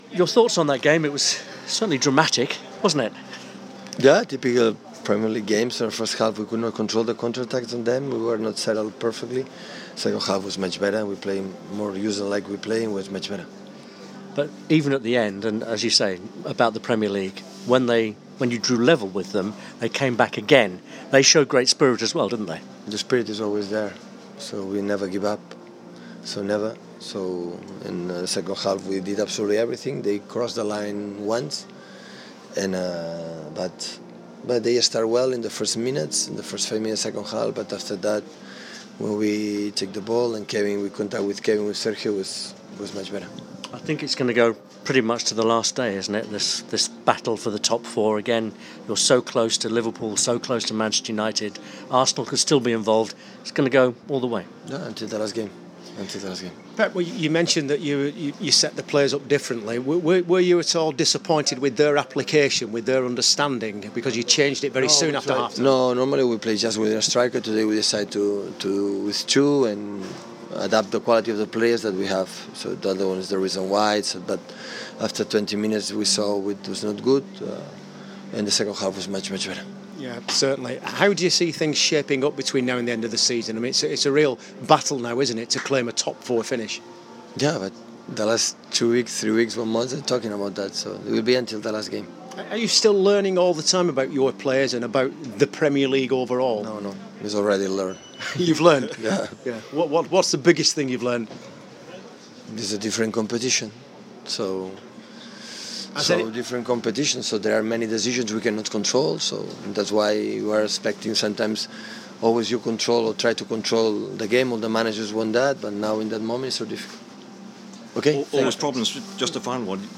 Manchester City manager Pep Guardiola reacts to the 2-2 draw away to Middlesbrough.